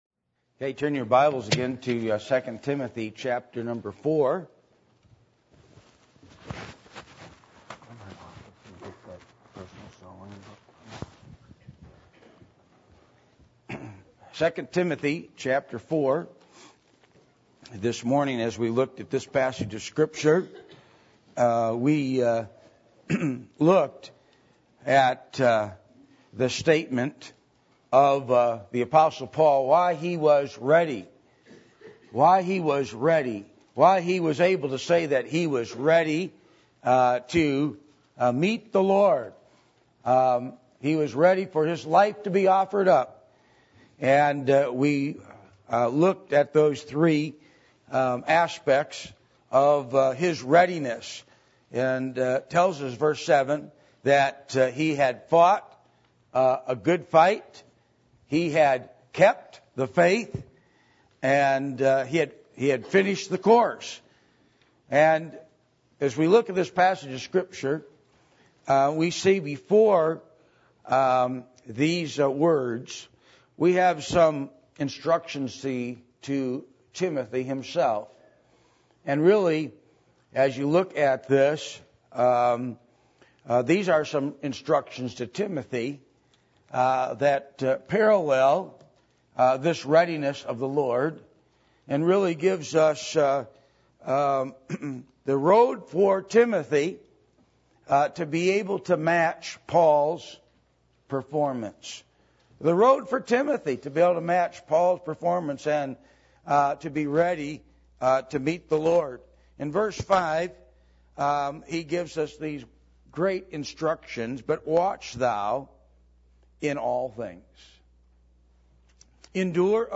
Passage: 2 Timothy 4:5 Service Type: Sunday Evening %todo_render% « The Last Days Of The Church Age